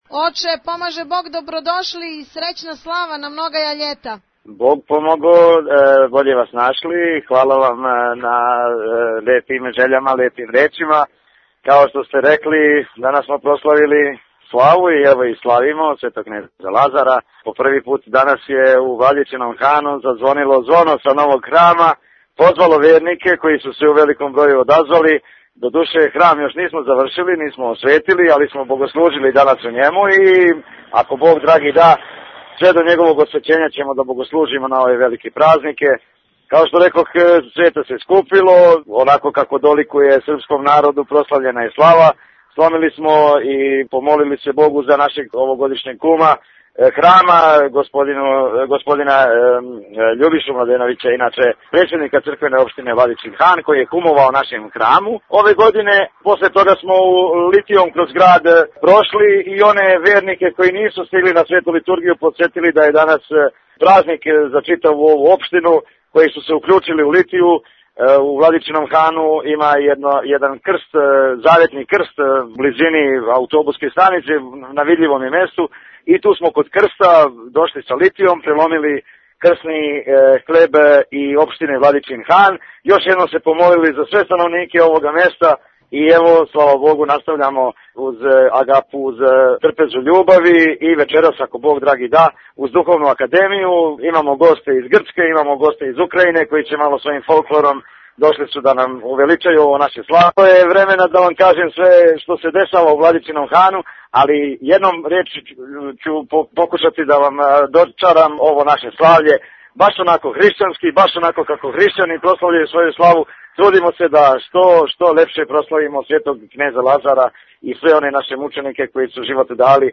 Актуелни разговори